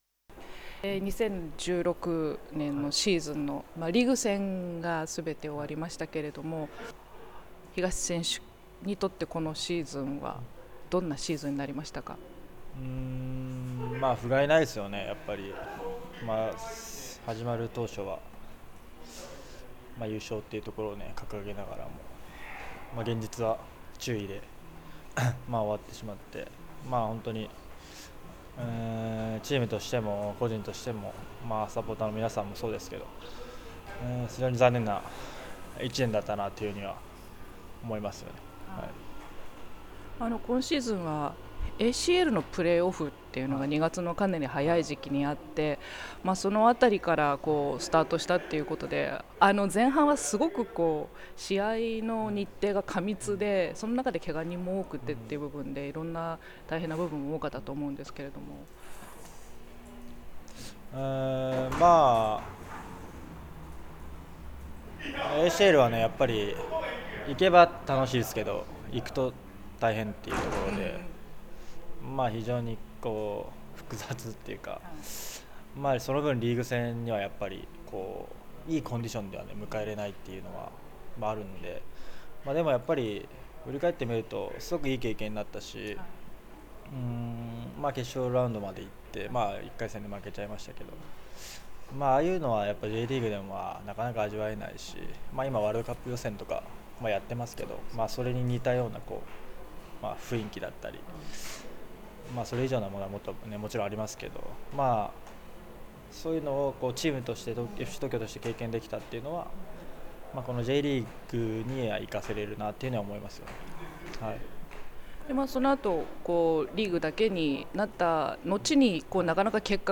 今回は12月1日(木)に「ゆうがた５」の〈FC東京百科〉でオンエアした 東 慶悟選手のインタビューです。
（取材環境により、音声に聴きづらい箇所がある点を、ご了承ください。取材日11月16日） １．苦しかった2016シーズンを振り返って感じたこと。